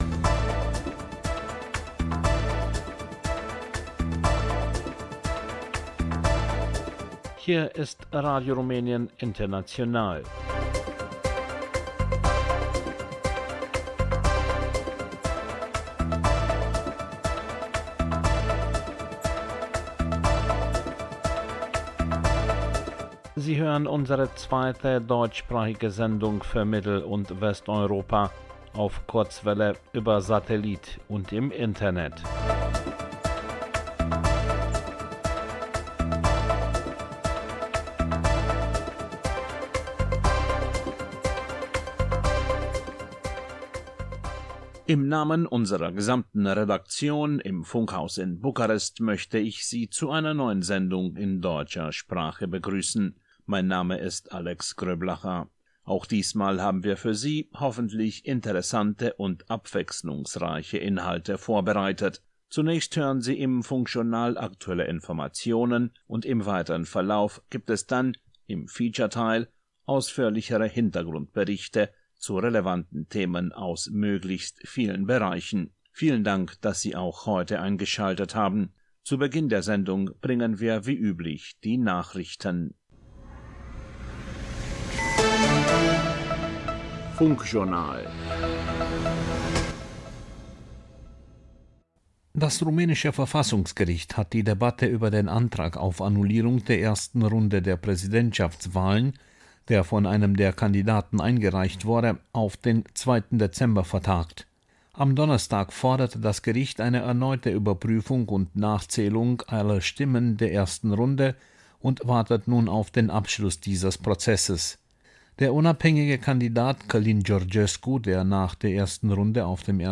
Nachrichten, Wochenspiegel, Kulturchronik, Rezept aus der rumänischen Küche, Rock & Pop, Programmrückschau